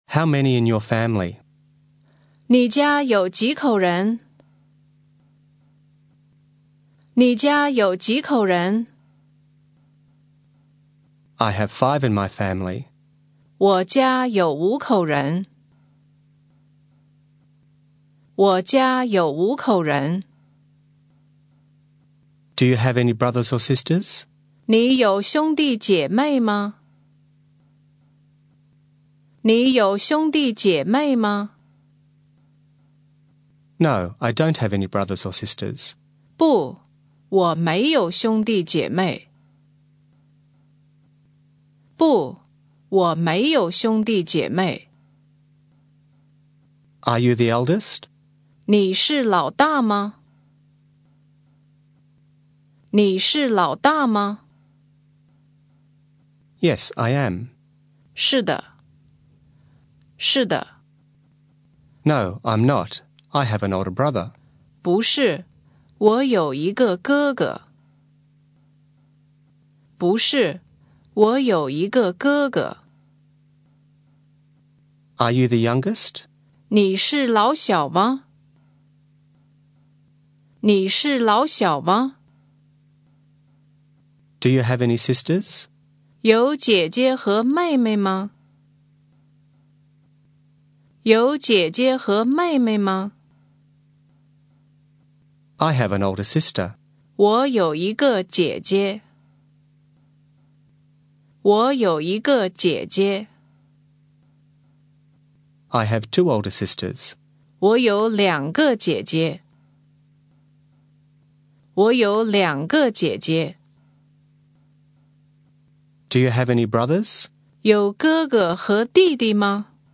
Listen and repeat